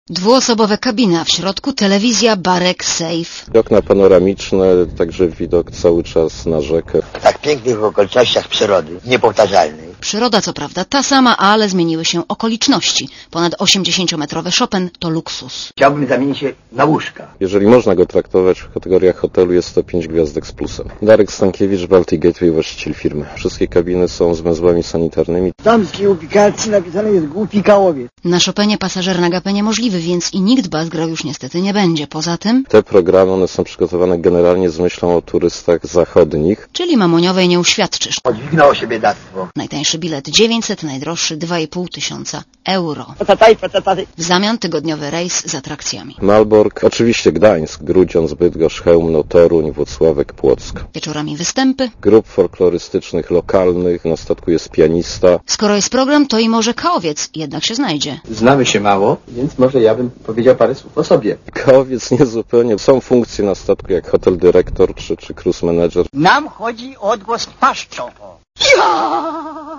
Posłuchaj relacji reporterki Radia Zet (271 KB)